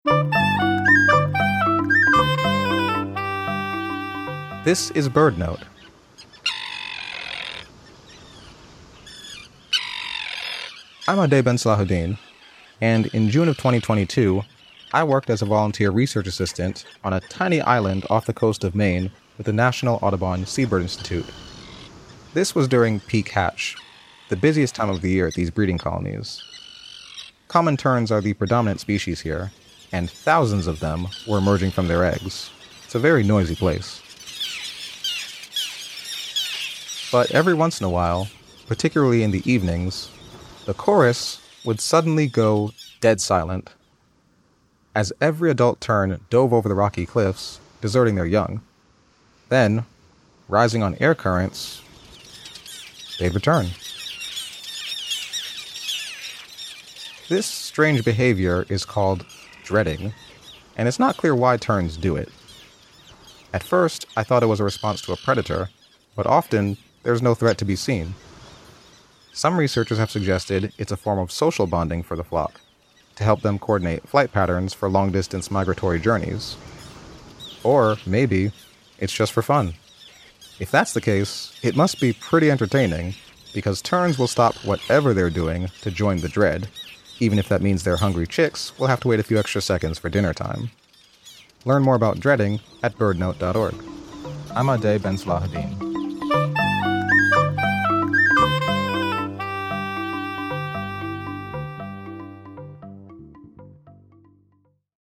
Every once in a while, the colony would suddenly go dead silent as all the adult terns took flight and dove over the rocky cliffs, returning soon afterward. This strange behavior is called a “dread,” and sometimes occurs without a predator nearby. It remains unclear why terns do it.